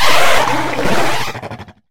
Fichier:Cri 1019 HOME.ogg — Poképédia
Cri de Pomdorochi dans Pokémon HOME.